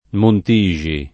vai all'elenco alfabetico delle voci ingrandisci il carattere 100% rimpicciolisci il carattere stampa invia tramite posta elettronica codividi su Facebook Montixi [sardo mont &X i ] cogn. — in qualche famiglia, adattato in Montisci [ mont & šši ]